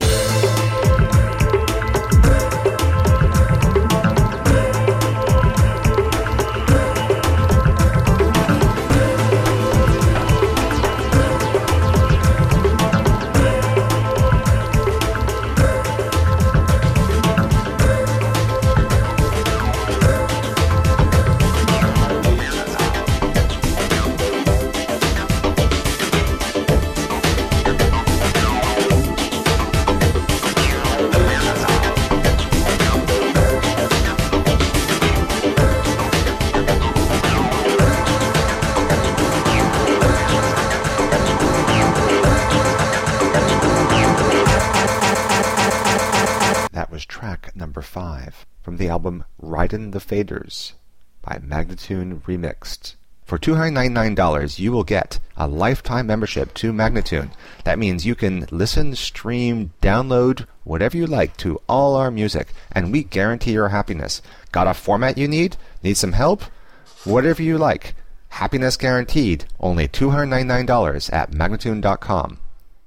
Electronica, Rock, Alt Rock, Remix